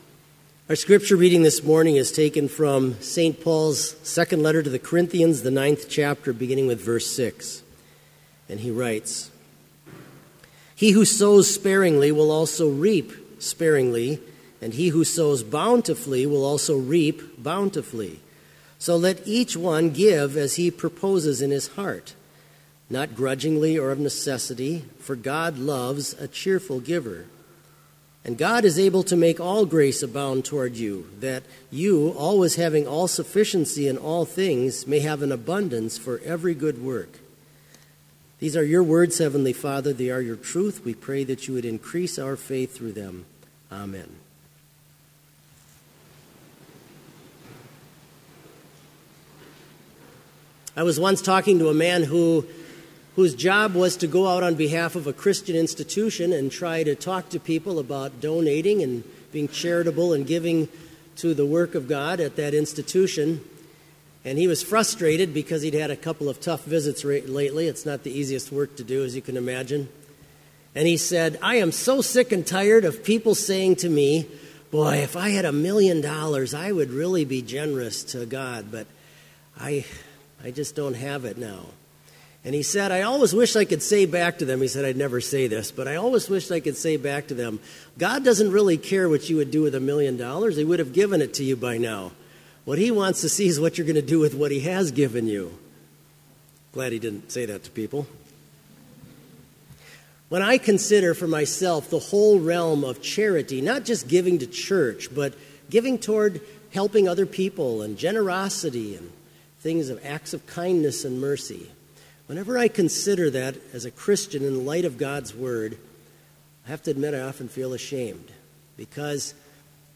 • Homily
This Chapel Service was held in Trinity Chapel at Bethany Lutheran College on Friday, September 2, 2016, at 10 a.m. Page and hymn numbers are from the Evangelical Lutheran Hymnary.